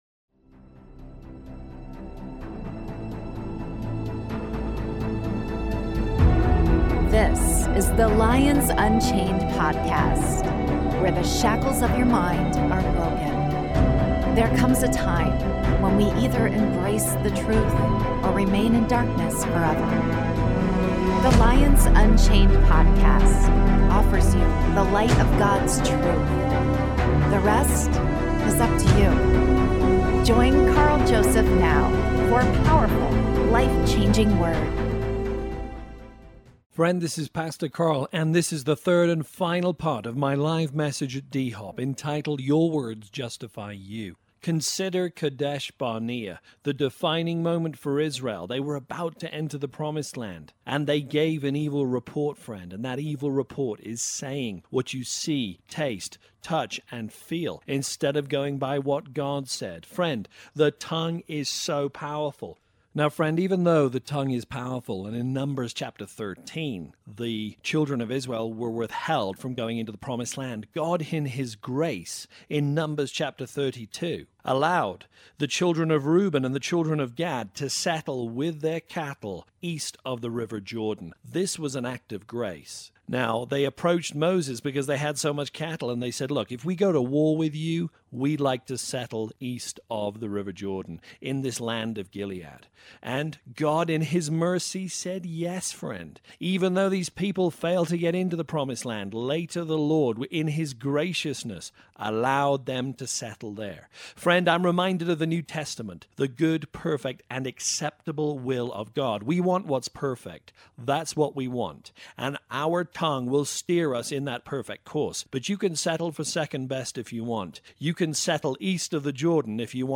Part 3 (LIVE)